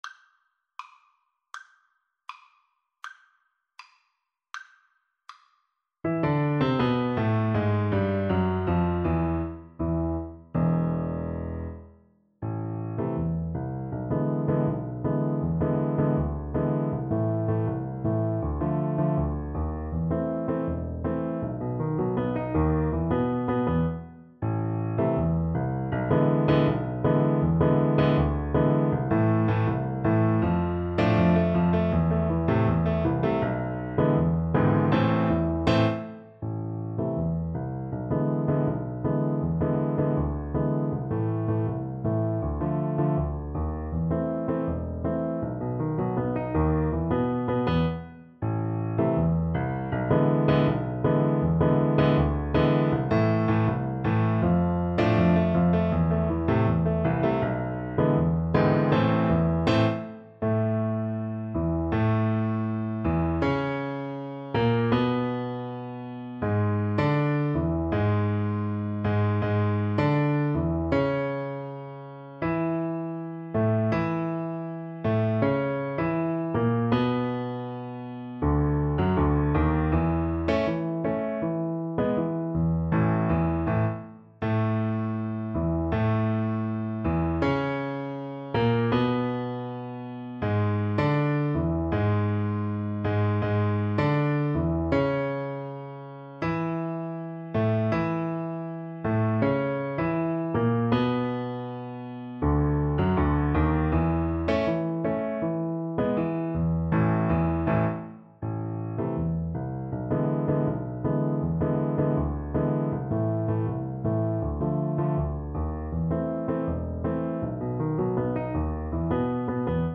Free Sheet music for Piano Four Hands (Piano Duet)
2/4 (View more 2/4 Music)
World (View more World Piano Duet Music)